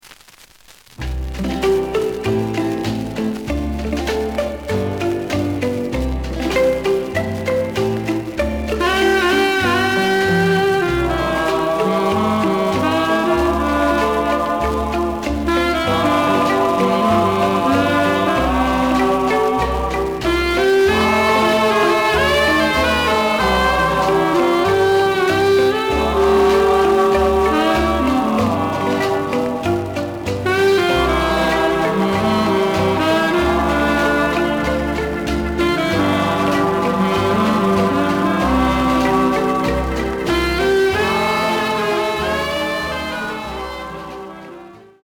The audio sample is recorded from the actual item.
●Genre: Rock / Pop
Noticeable noise on B side.